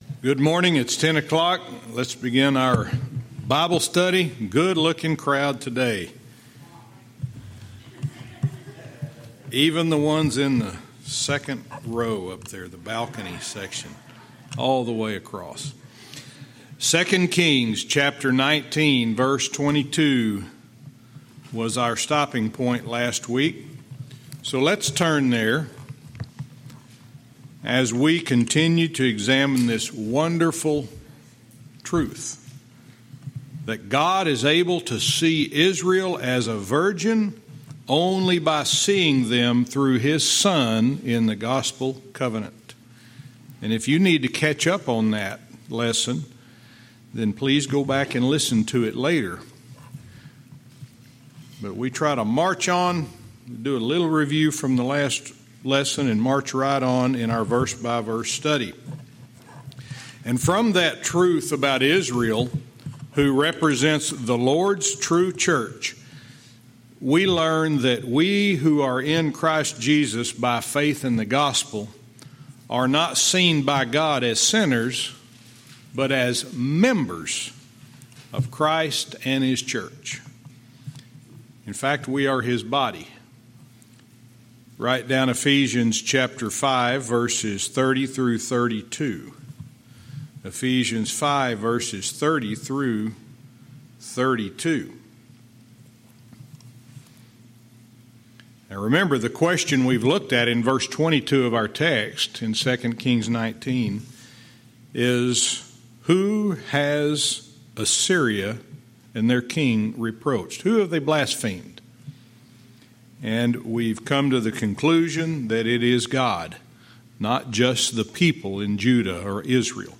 Verse by verse teaching - 2 Kings 19:22(cont)